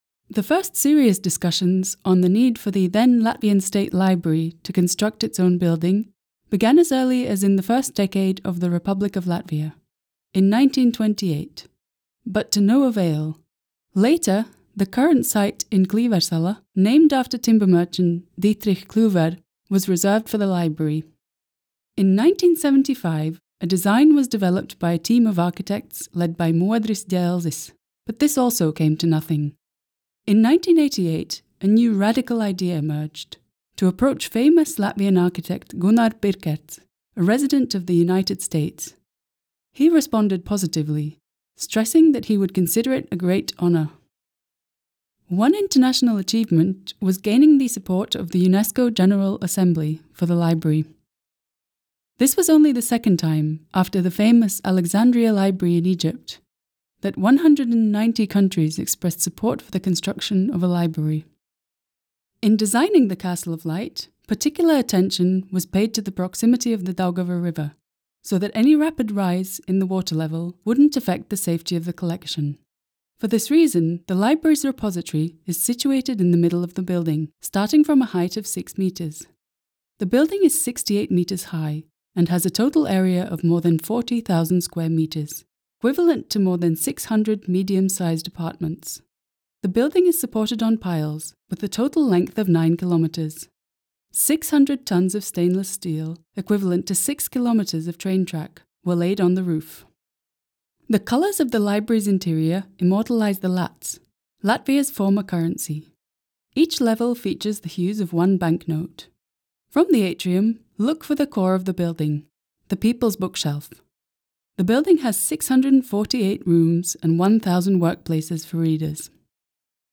balss aktieris
Tūrisma gidi
Digitāli radīts